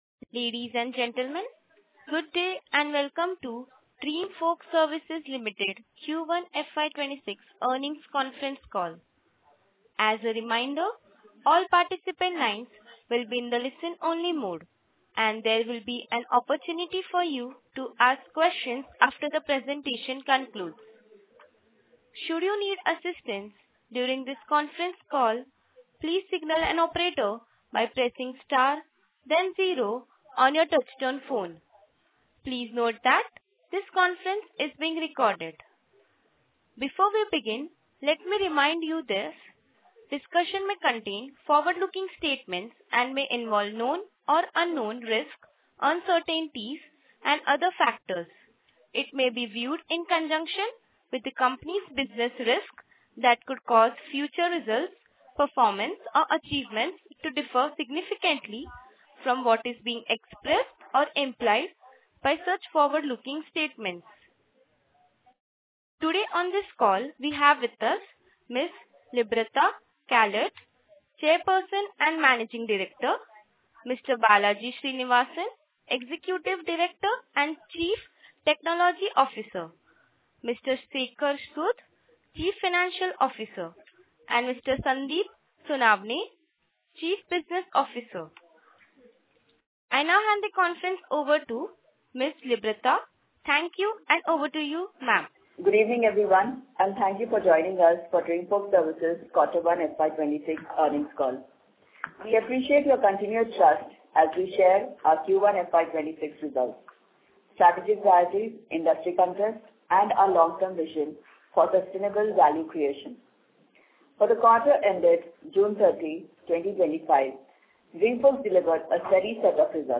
Concalls
Q1-FY25-earnng-confrence-call.mp3